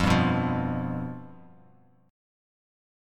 Fsus2b5 chord